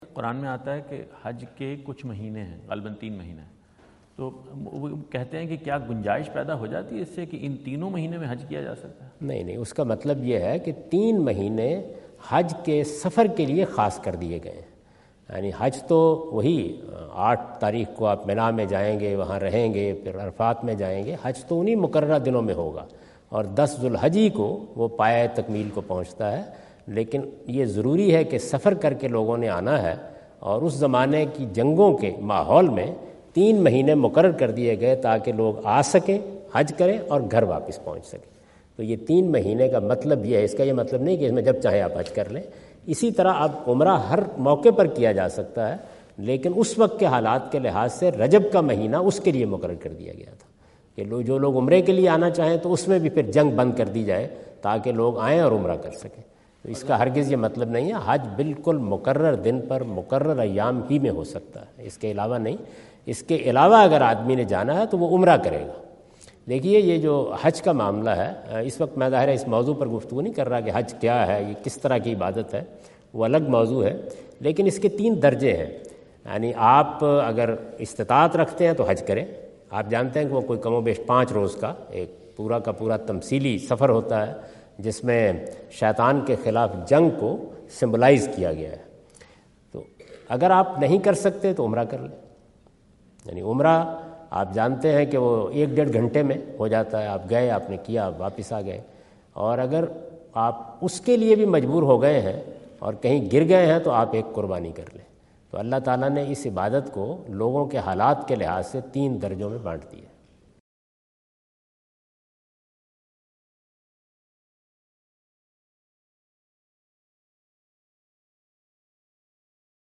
Category: English Subtitled / Questions_Answers /
In this video Javed Ahmad Ghamidi answer the question about "sacred months for Hajj and Umrah" asked at North Brunswick High School, New Jersey on September 29, 2017.